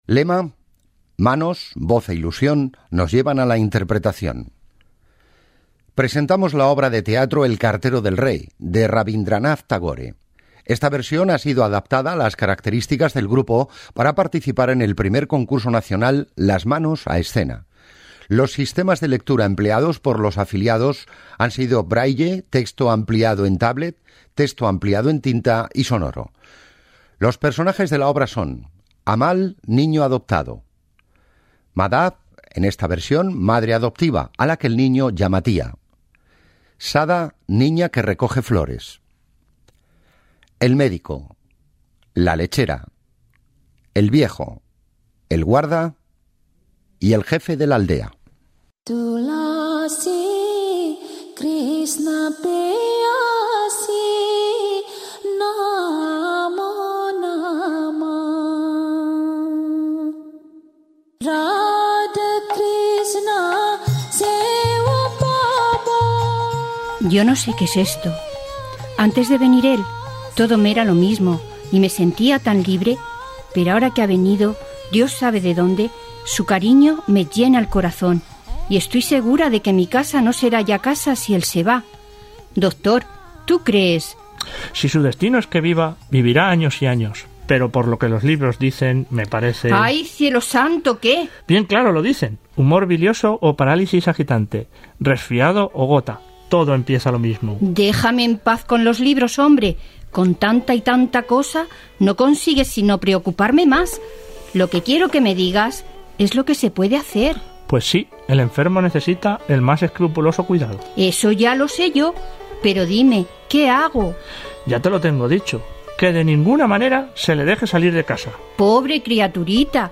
Teatro leído
Archivos de audio del Teatro Leído de la Biblioteca Digital ONCE